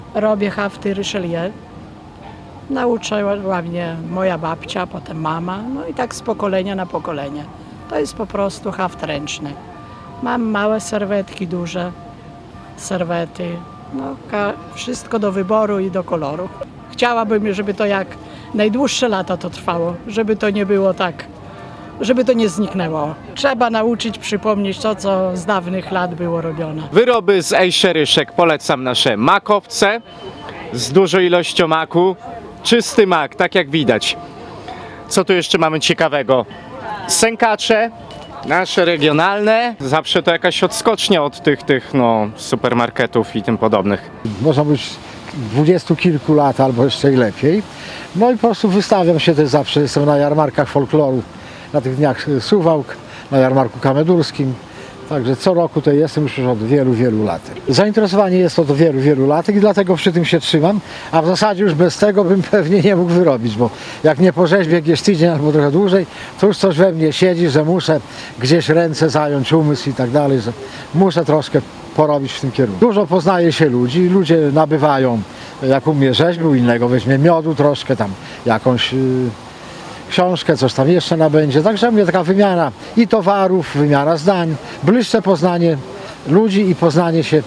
– Należy podkreślać wyjątkowość naszych regionalnych wyrobów – mówili wystawcy.
wystawcy-na-jarmarku-folkloru.mp3